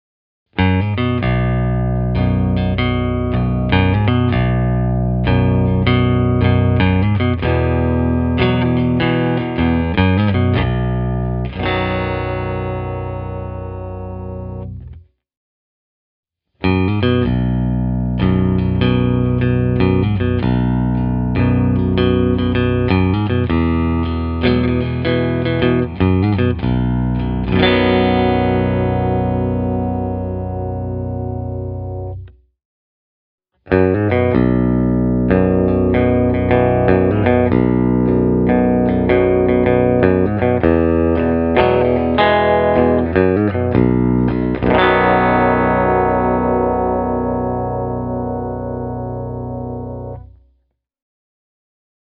The freshness of the neck pickup, together with its deliciously woody response, will keep even the lowest runs clear and punchy.
Listen to these two audio clips (the pickup order is: neck – both – bridge):
Hagström Viking Deluxe Baritone – clean